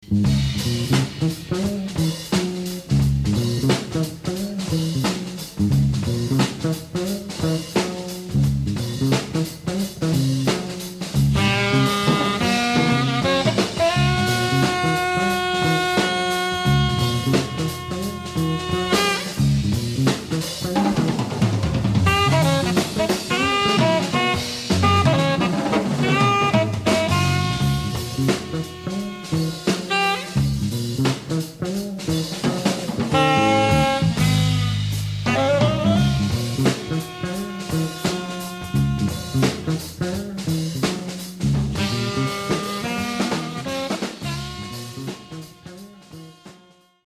Tenor and Soprano Saxophone
Electric Bass
Drums and Congas
Recorded Live in Amsterdam, The Netherlands in 1977